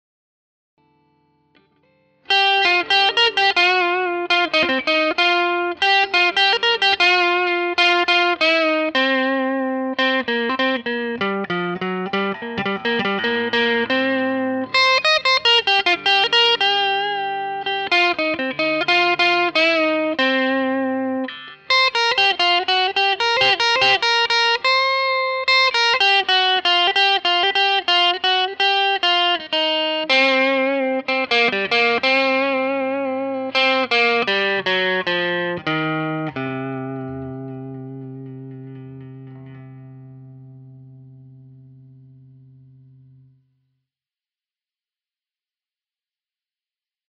· Solid body.  Quartersawn mahogany. Hand carved.  Red transparent lacquer finish.
· Seymour Duncan Pick-ups: Jazz and JB.
Click to Hear Audio Sample—Neck Position Pickup